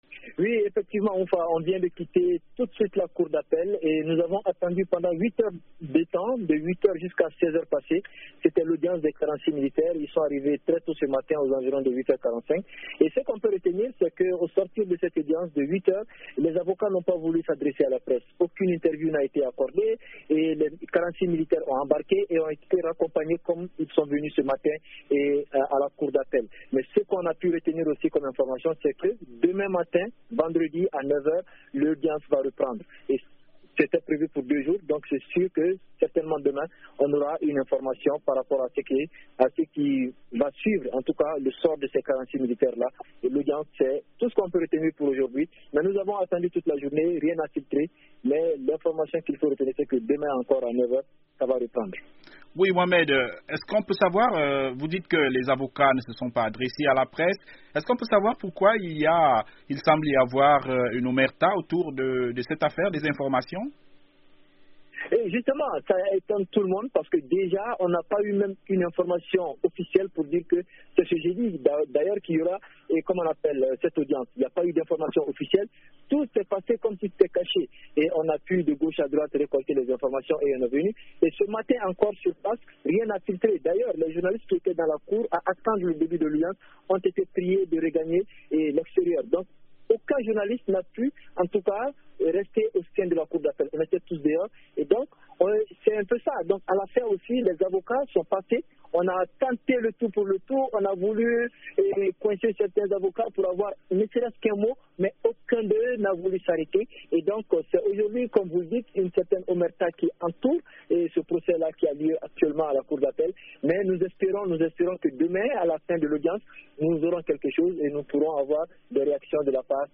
Le procès des 46 militaires ivoiriens soupçonnés d'être des "mercenaires" et détenus au Mali depuis juillet a été renvoye à demain par la Cour d'appel de Bamako. Nous avons joint notre correspondant sur place